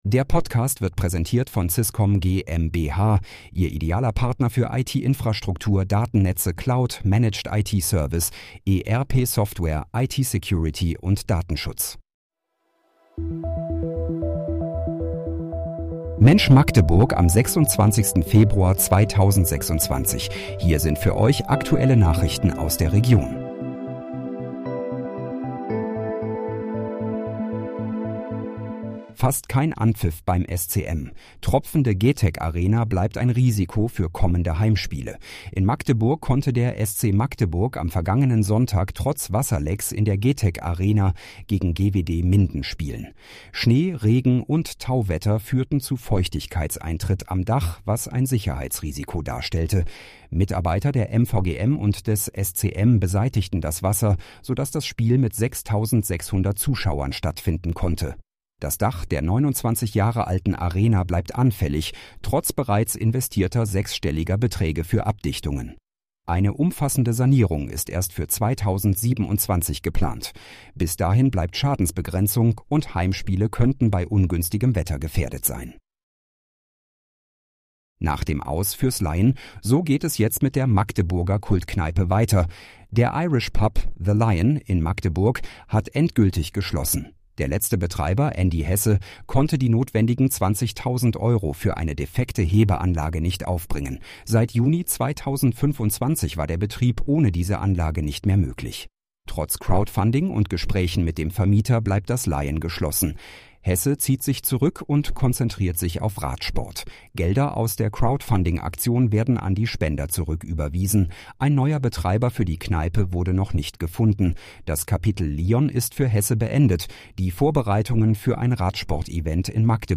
Mensch, Magdeburg: Aktuelle Nachrichten vom 26.02.2026, erstellt mit KI-Unterstützung